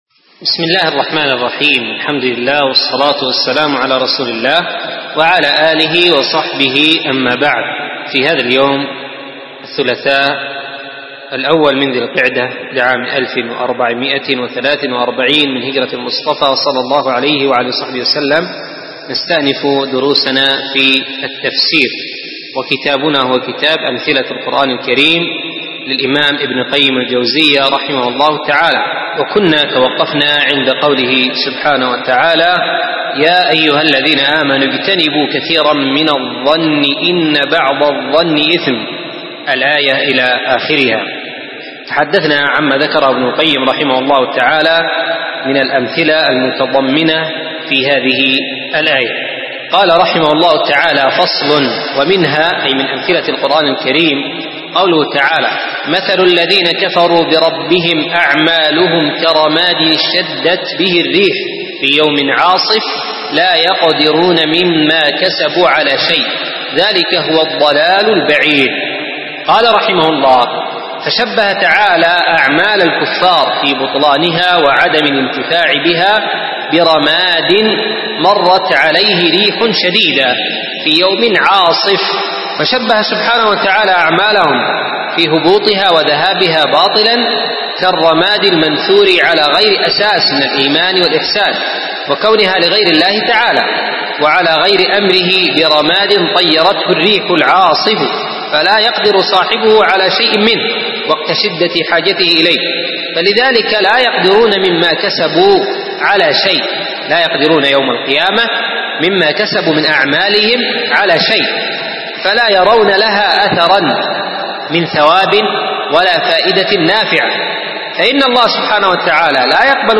شرح أمثلة القرآن الكريم ( الدرس الثامن ).mp3